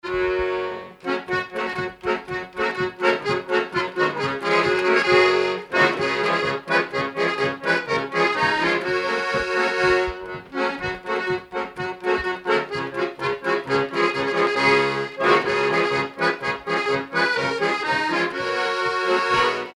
Magland
danse : marche
Pièce musicale inédite